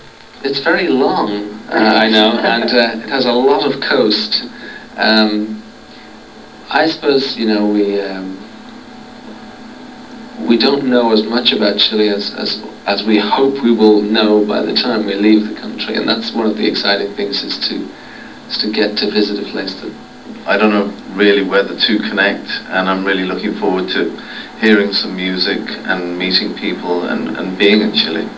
Here you´ll find many pictures of the band in Santiago and a couple of sounds from the press conference they gave when they arrived.
The Edge and Adam Clayton talking about their expectations of visiting Chile